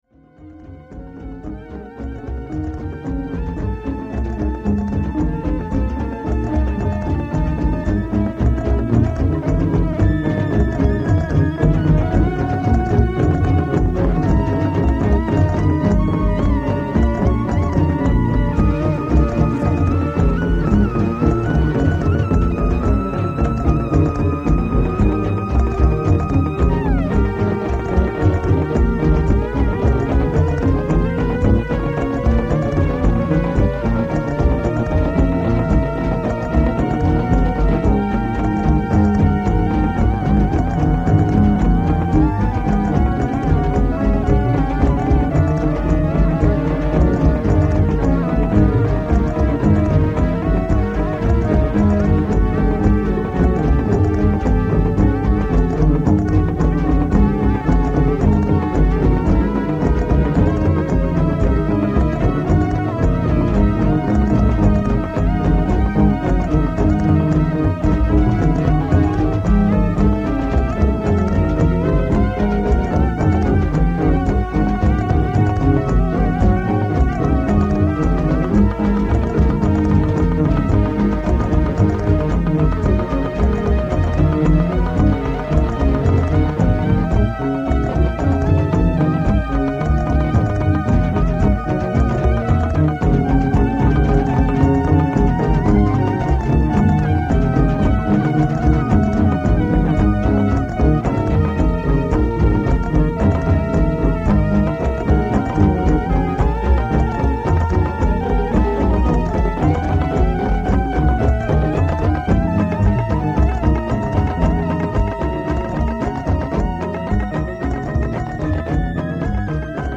Recorded in 1988 and 1989 in my home studio in San Francisco
Middle Eastern inspired tracks